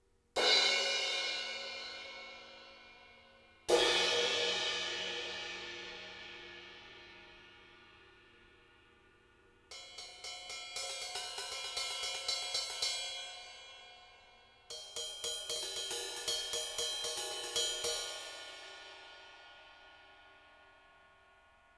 To show the results of the mod, I recorded samples using both the modded 205 and a stock version of the same mic.
Note, though, that I haven’t applied any post-processing to the sounds.
Cymbals (crash, then ride)
Modded mic – Cymbals [3.8Mb]
The moded mic has a beefier bottom end, and a softer top end.
Apex-205-Mod-Cymbals.wav